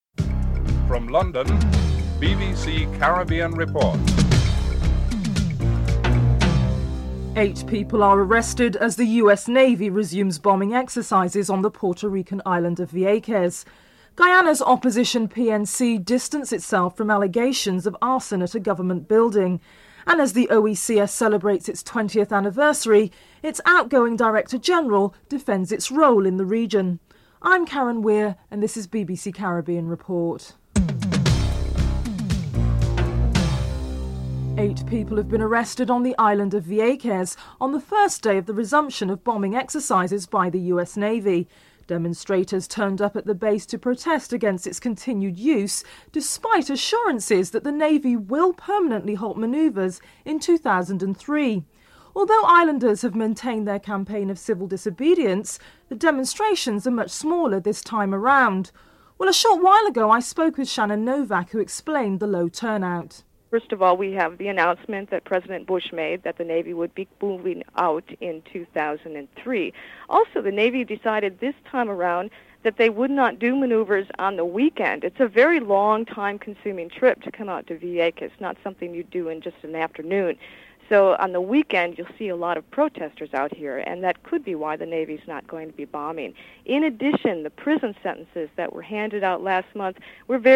1. Headlines (00:00-00:33)
Prime Minister Pierre Charles is interviewed (10:48-13:26)